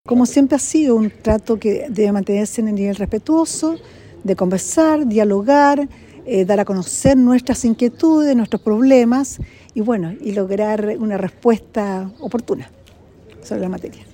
Sus declaraciones las entregó en el contexto de la inauguración del nuevo Juzgado de Garantía y Tribunal Oral en lo Penal de Osorno, región de Los Lagos; edificio con una superficie de 5.231 metros cuadrados que presta servicios a una población superior a 220 mil personas de la provincia de Osorno.